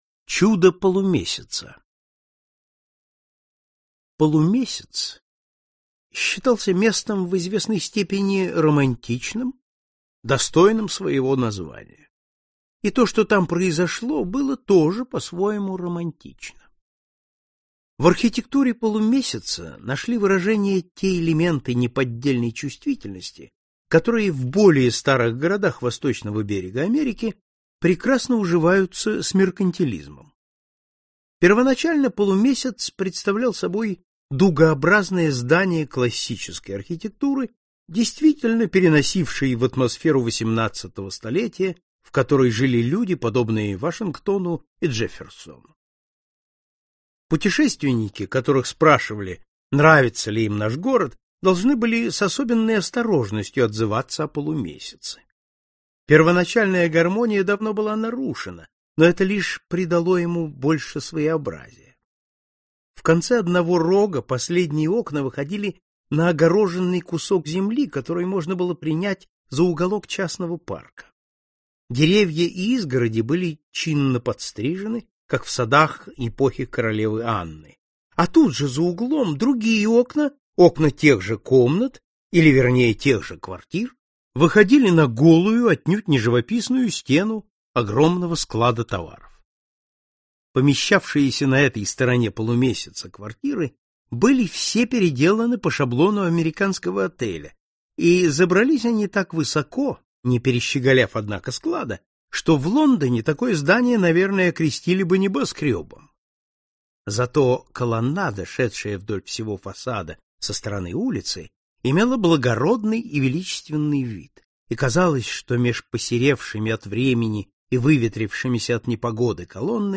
Аудиокнига Приключения патера Брауна | Библиотека аудиокниг